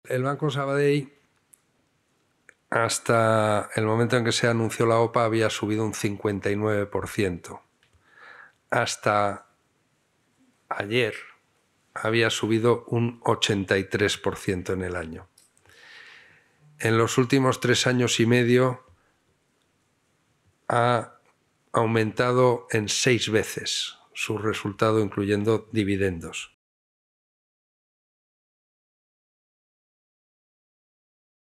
Material audiovisual de la rueda de prensa